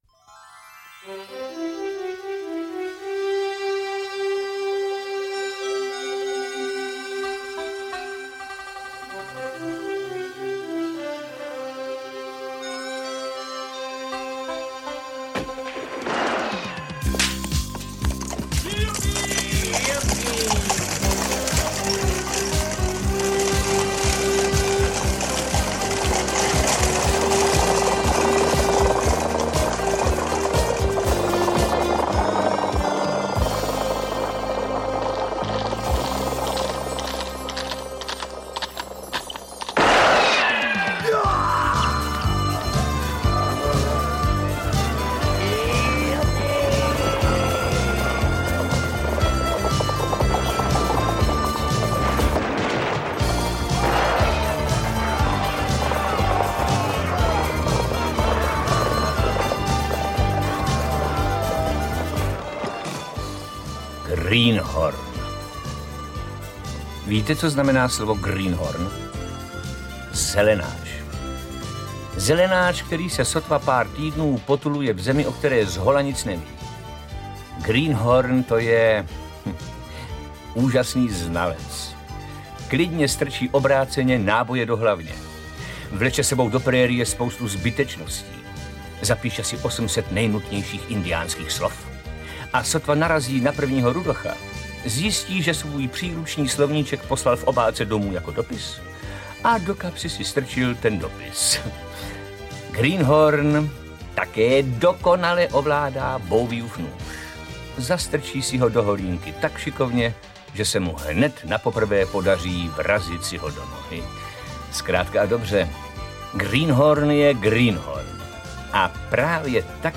Audiokniha
stáhnout ukázku Varianty: Vyberte Audiokniha 189 Kč Kniha vazba: pevná 354 Kč Kniha vazba: pevná 442 Kč CD 293 Kč Další informace: Čte: Miroslav Moravec, Rudolf Hrušínský,…